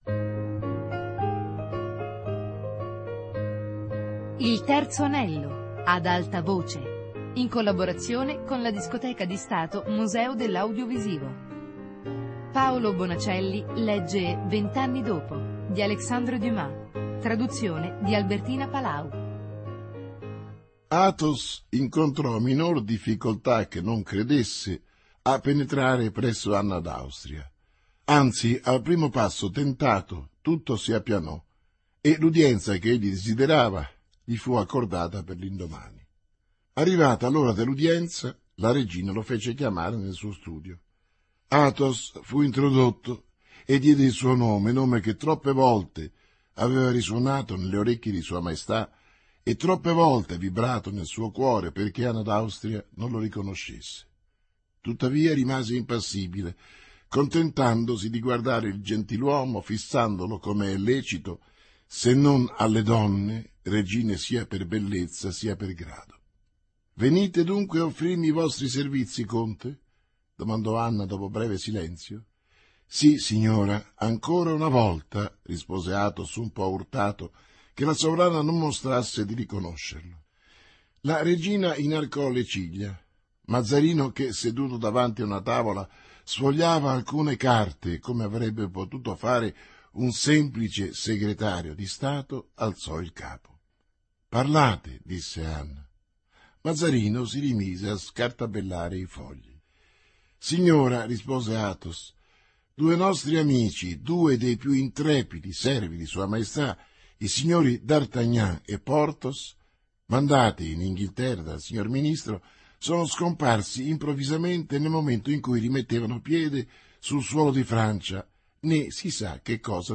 Vent'anni dopo - Lettura XXXVIII